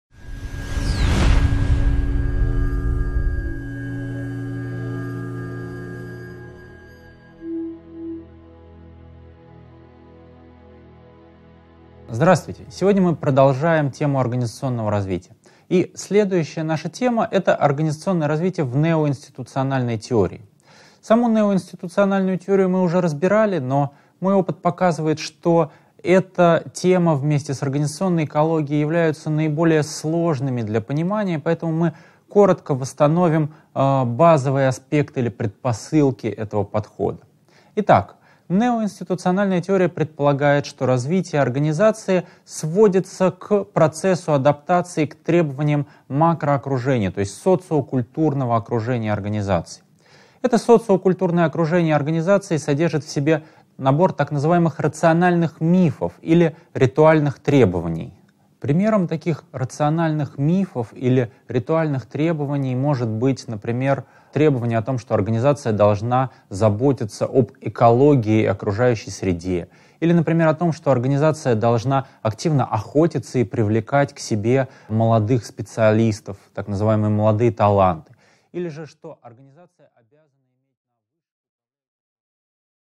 Аудиокнига 10.1. Неоинституциональная теория организационного развития | Библиотека аудиокниг